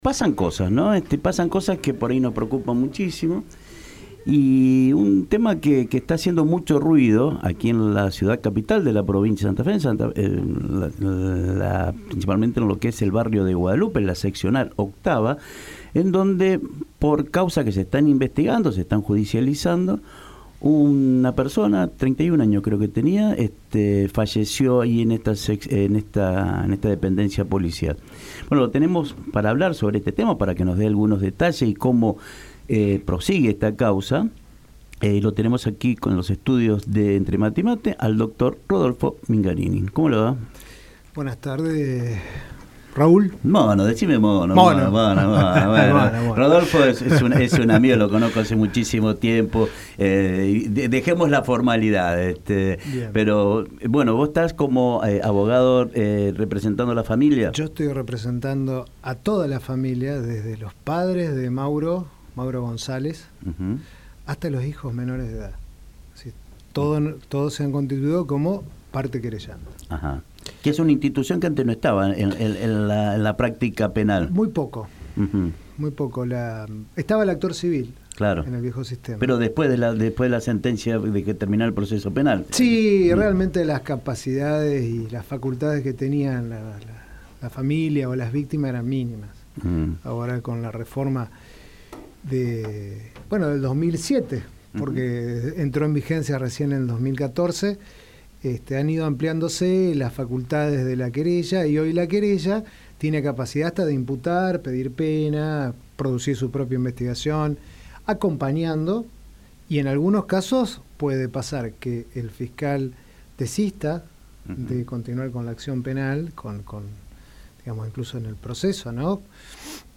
A continuación, la entrevista completa.